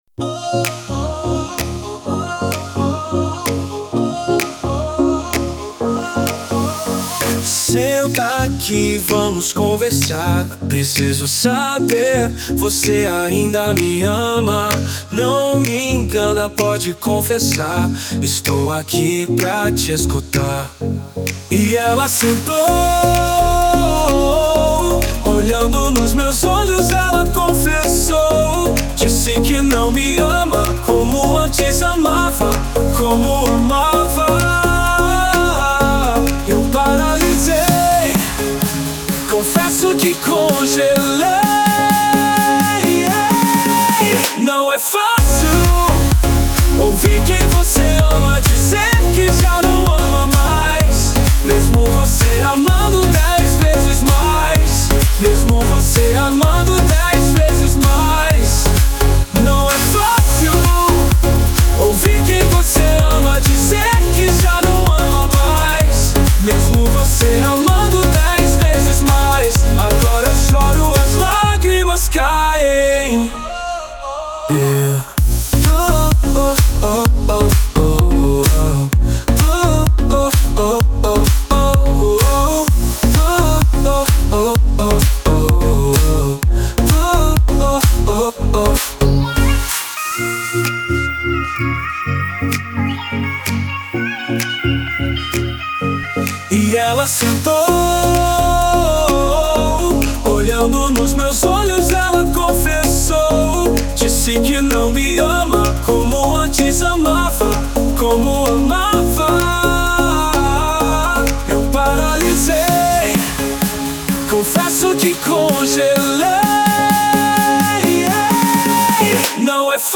ELETRÔNICAS